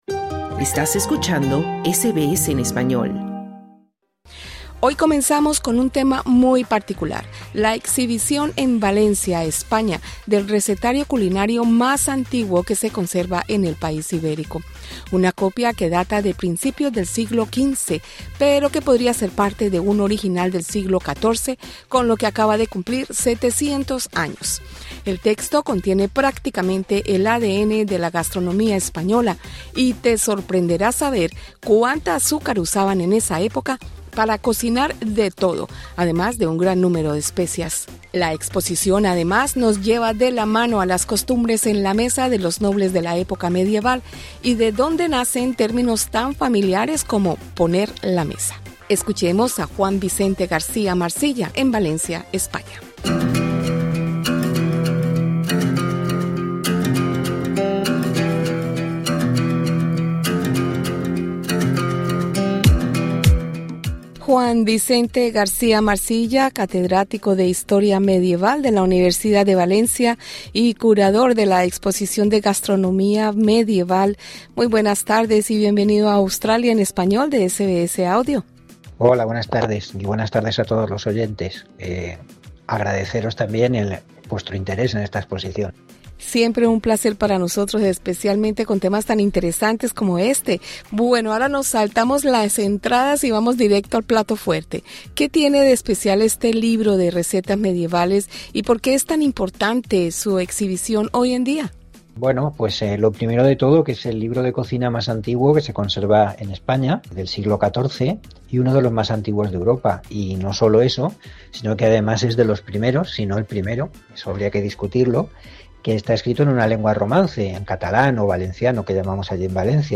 Un libro de recetas español de 700 años fue exhibido en Valencia, con otras piezas y objetos curiosos que usaban los nobles de la época medieval. Entrevista